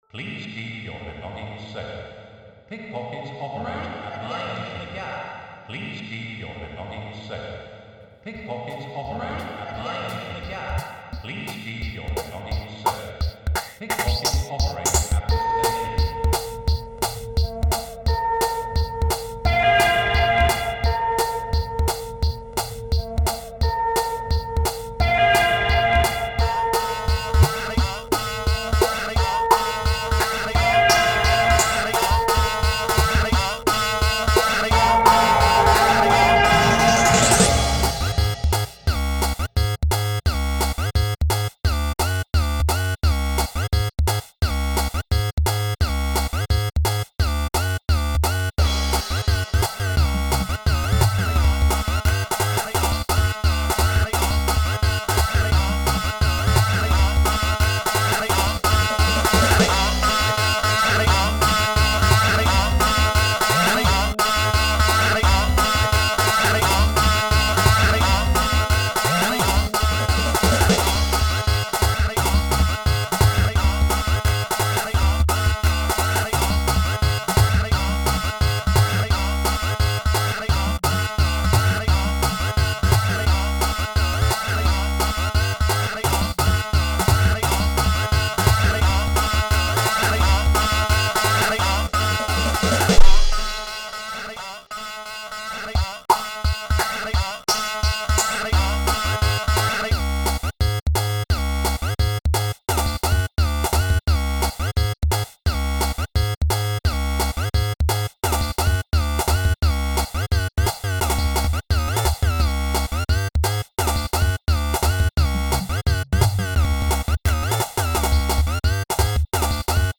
173 BPM